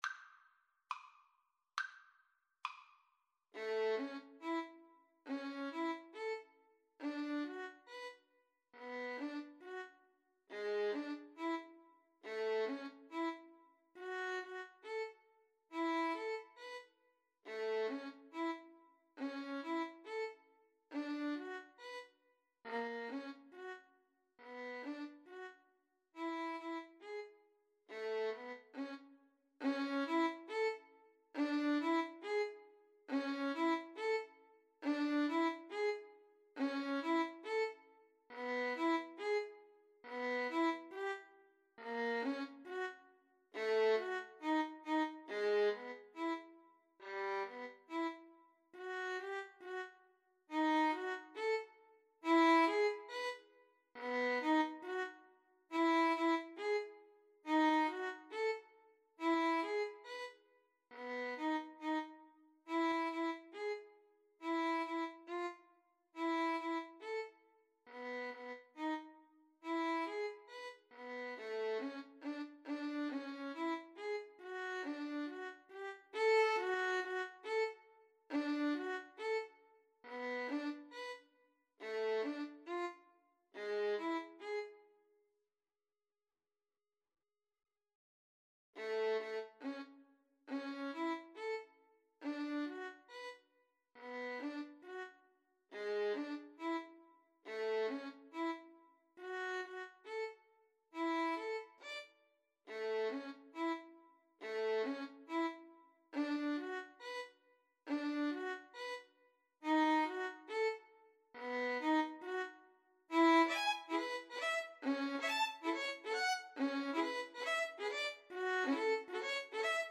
2/4 (View more 2/4 Music)
~ = 69 Allegro grazioso (View more music marked Allegro)
Classical (View more Classical Violin Duet Music)